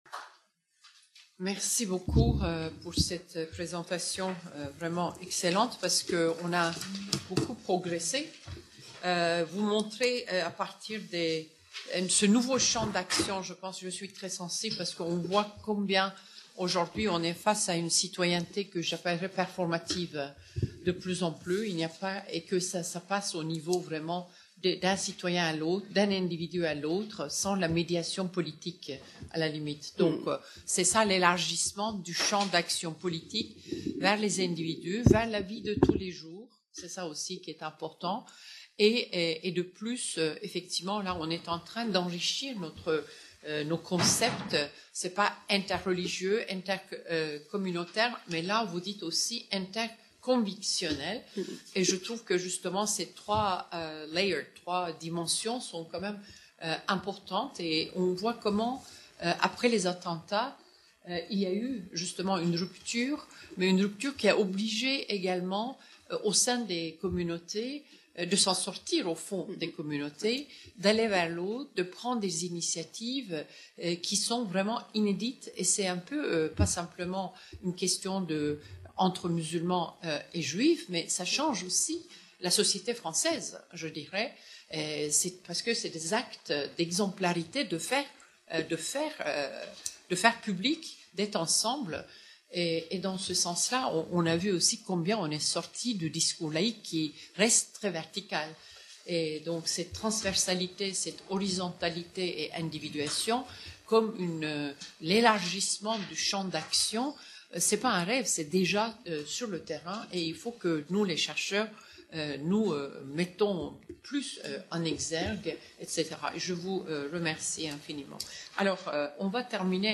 Since the fall-out from the Paris attacks and alongside the rise in nationalism across Europe, faith leaders have sought to improve community relations. This panel brings together practitioners and researchers to discuss the notion of interreligious encounter in relation to social action and its potential to generate constructive communication.